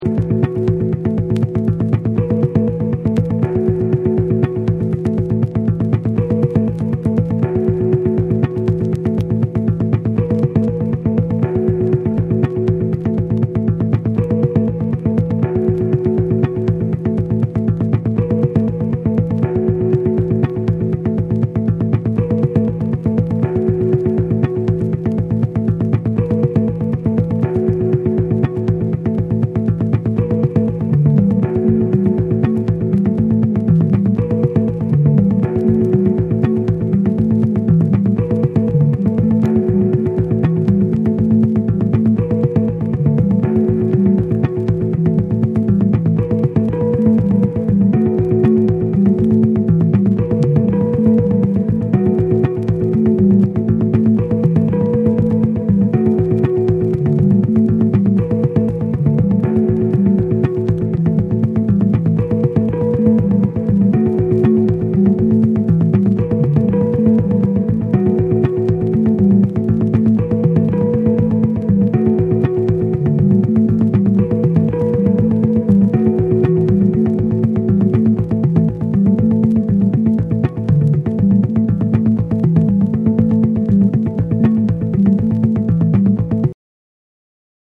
その他ビート、上音のパーツを収録。
JAPANESE / BREAKBEATS